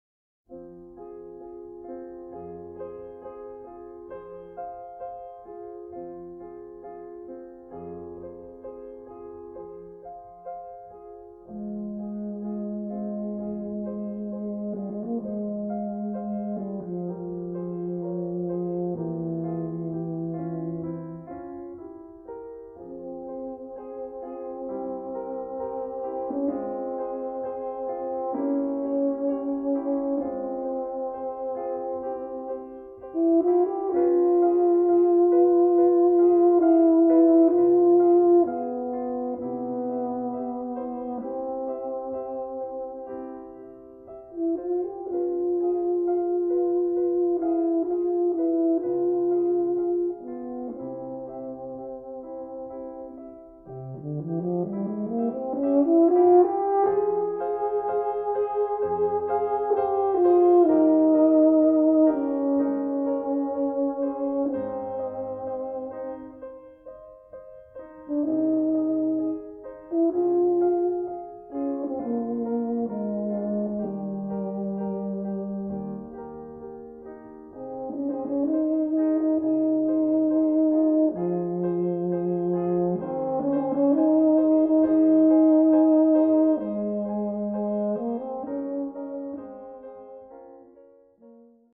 For Euphonium Solo
with Piano.